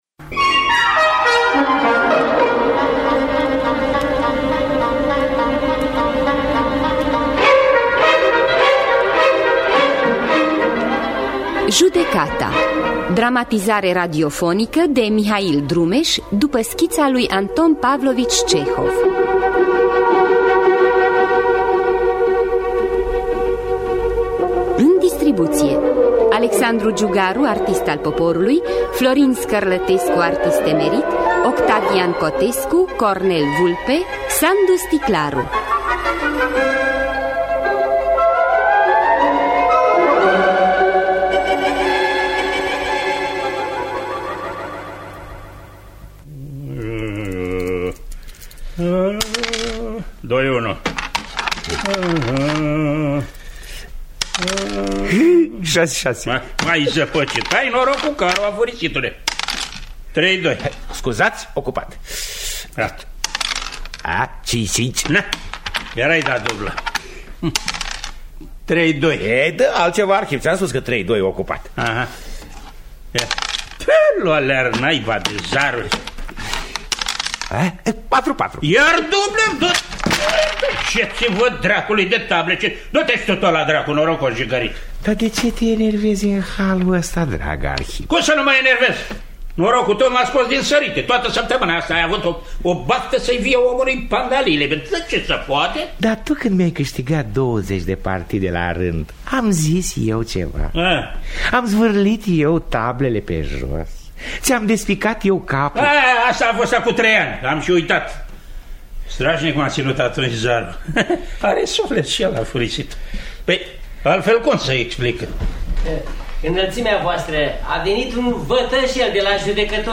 “Judecata” de Anton Pavlovici Cehov – Teatru Radiofonic Online